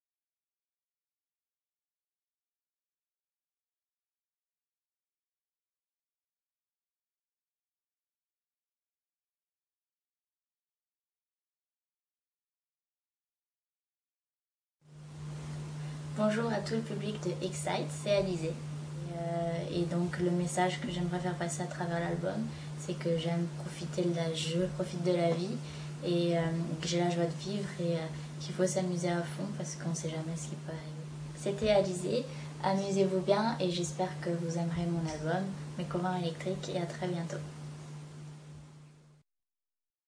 Annonce faite pour "Excite" au Japon.